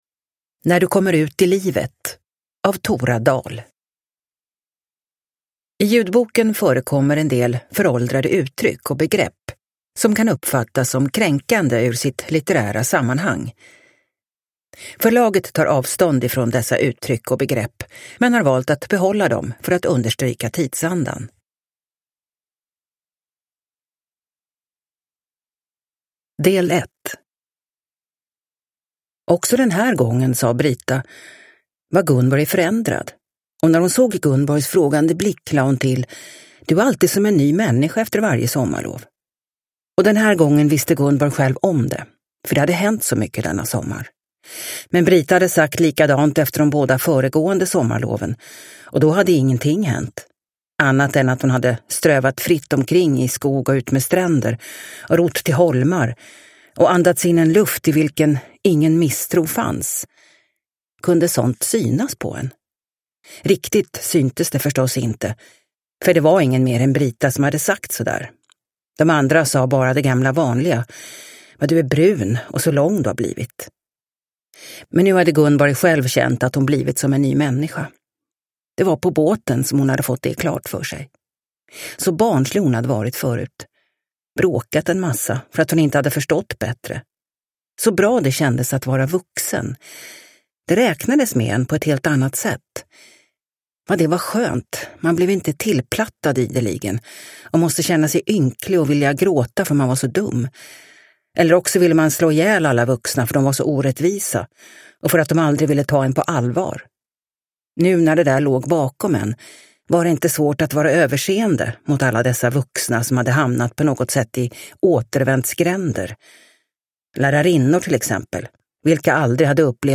När du kommer ut i livet – Ljudbok – Laddas ner